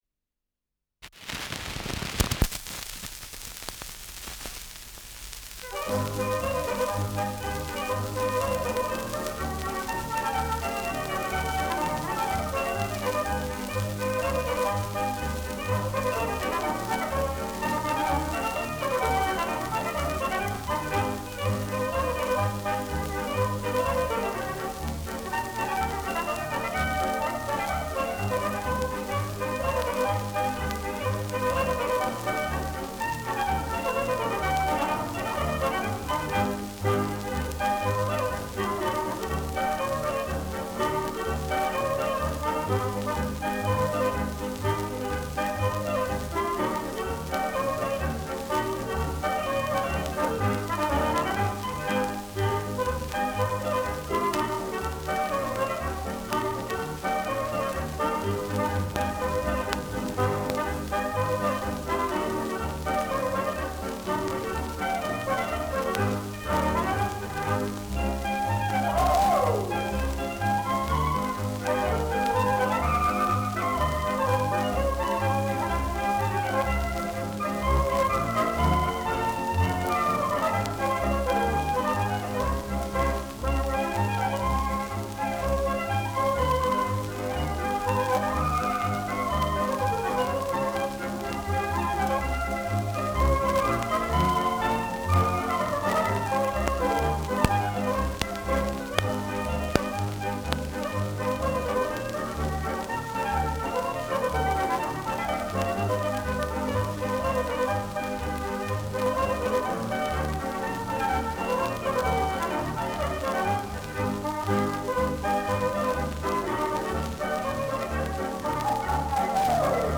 Schellackplatte
Ländlerkapelle* FVS-00018